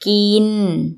ginn